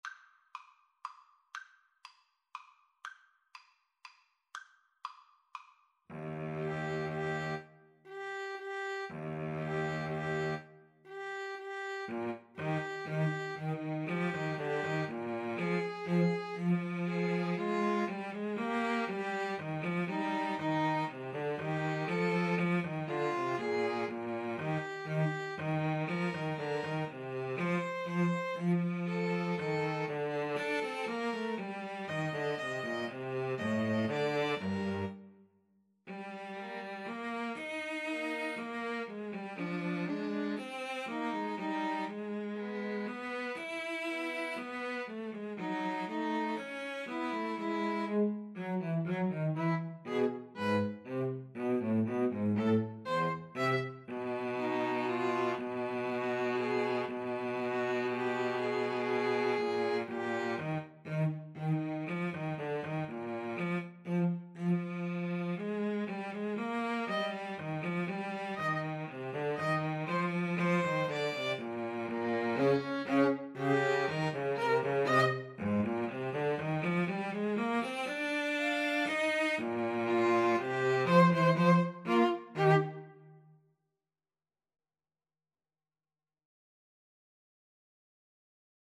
Eb major (Sounding Pitch) (View more Eb major Music for 2-Violins-Cello )
Allegretto pomposo = c.120
2-Violins-Cello  (View more Intermediate 2-Violins-Cello Music)